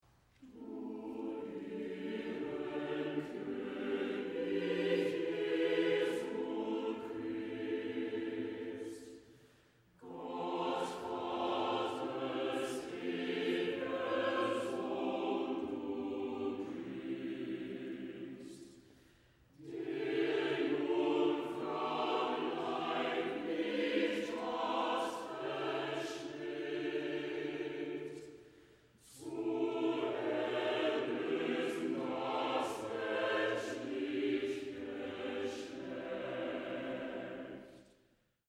Dame Jane Glover, conductor
Music of the Baroque Chorus and Orchestra
while the Blasphemers cry for help.